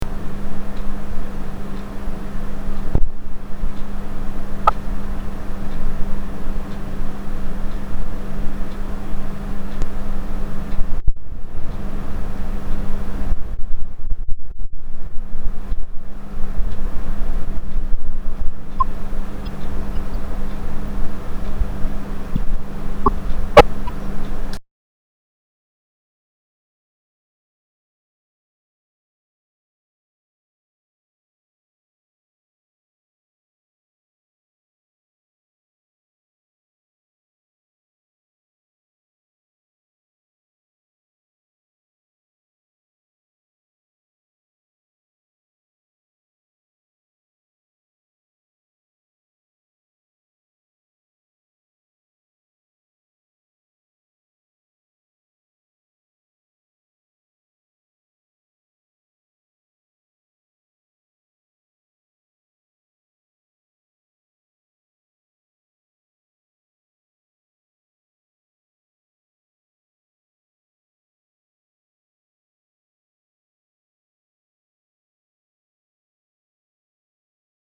Ghost Wind-noise on condenser microphone
Have been using a blue spark condenser mic for the past two years or so. had no problems till recently. when i boost the gain to usable levels i hear very bad wind noise that peaks the mic.
View attachment Noise.mp3 The noise clip is only during the first 25 secs. Normal expected sounds are the clock ticking in the background and the hum from my aircond.